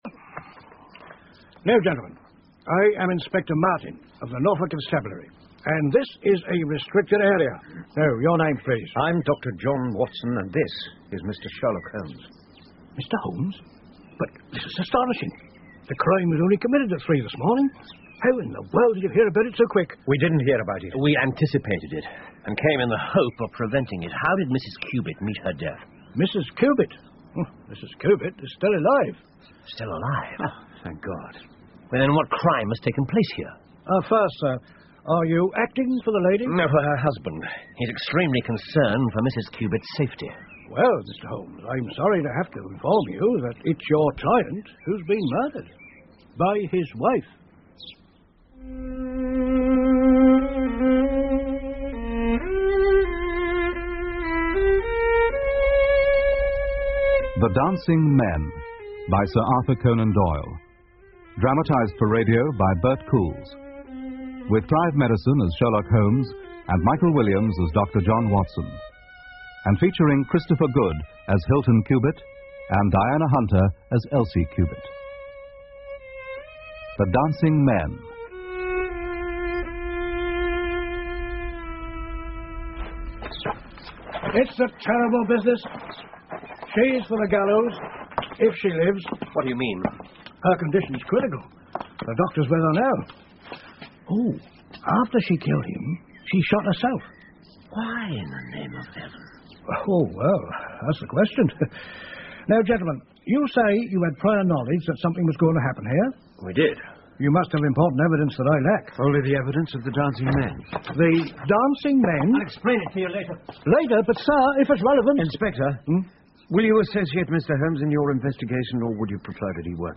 福尔摩斯广播剧 The Dancing Men 1 听力文件下载—在线英语听力室